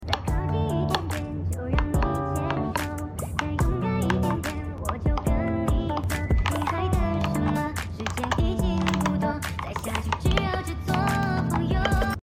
This adorable keyboard isn’t just for typing — it’s for ✨enjoying every moment✨ at your desk. 🌸 Soft pastel colors that instantly cheer up your workspace 🎶 ASMR-style key sounds for the ultimate typing satisfaction 📚 Perfect for school, study, or your cozy home office 💫 Because productivity can be pretty!